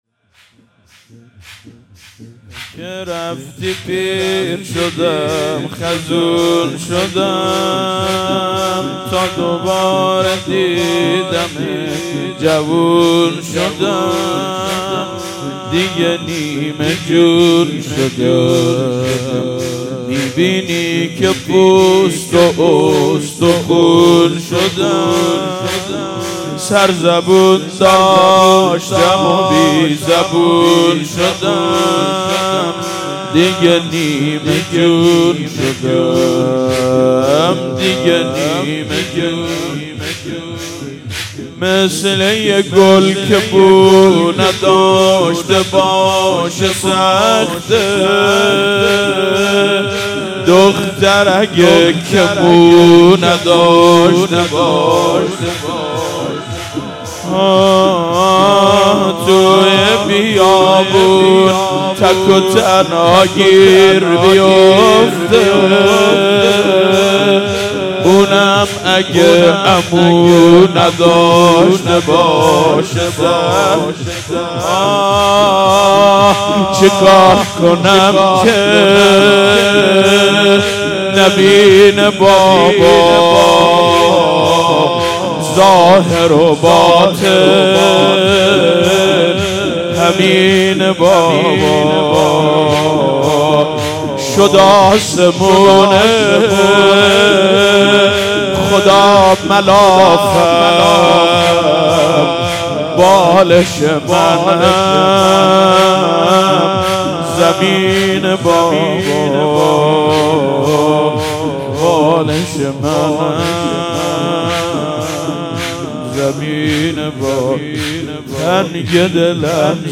مراسم هفتگی/15آذر97